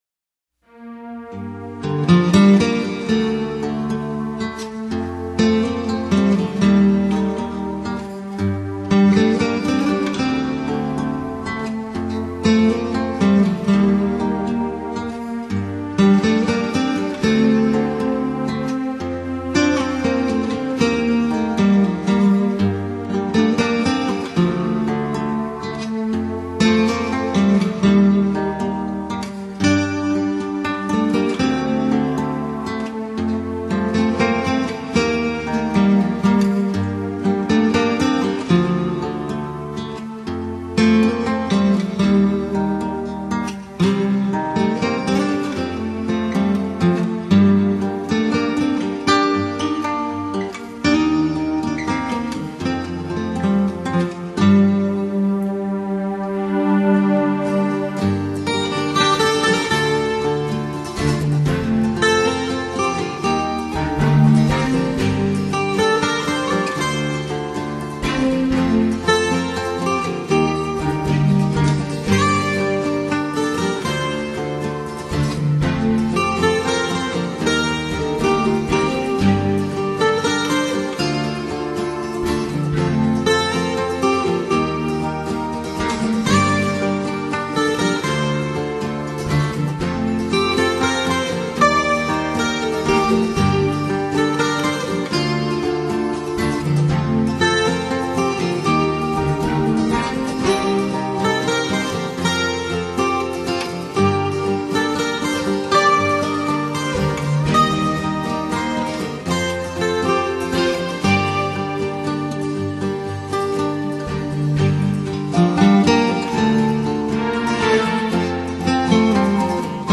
风格：New Flamenco
本片结合吉普赛人流浪的情结，加上东方的迷幻多情，使整张专辑营造出一幕幕幽远意象，如泣如诉地演奏出感人动听的旋律。